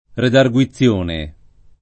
redarguzione [redarguZZL1ne] s. f. — così, con forma etimologicam. regolare (dal lat. redargutio -onis), nel sign.